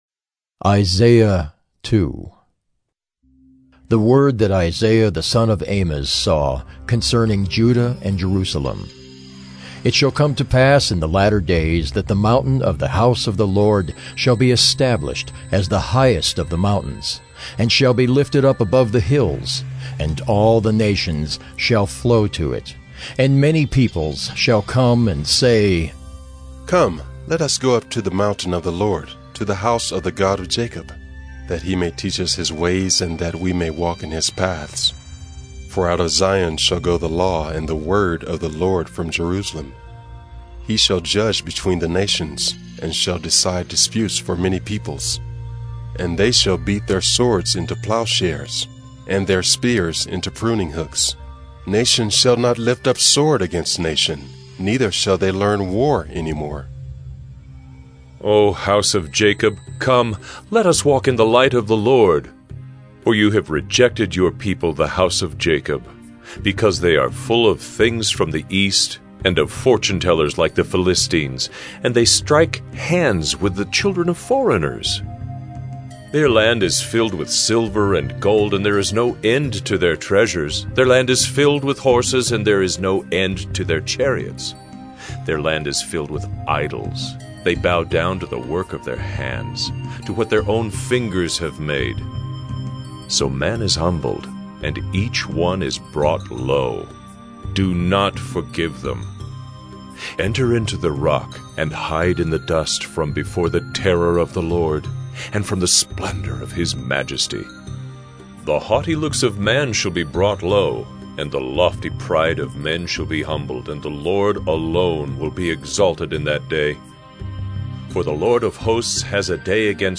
“Listening to God” Bible Reading & Devotion: Jan 25, 2021 – Isaiah 002 – 萬民福音堂「聽主話」讀經靈修